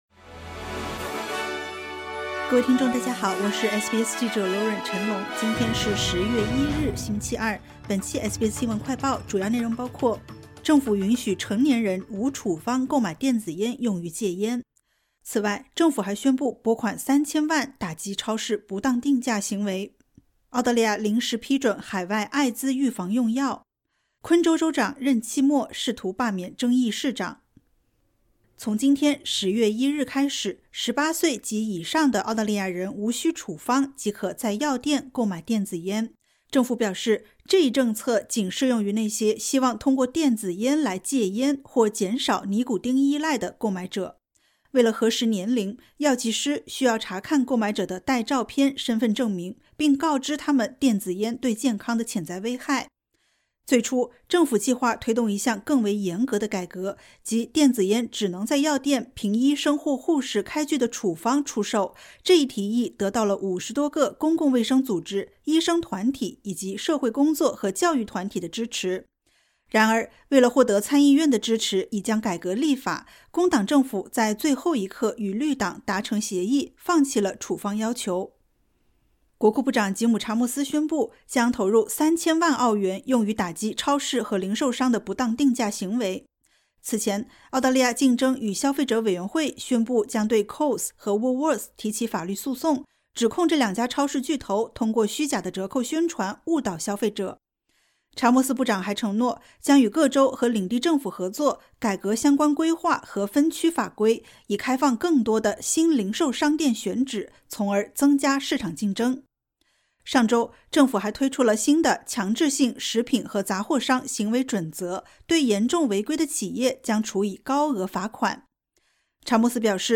【SBS新闻快报】政府允许成人无处方购电子烟用于戒烟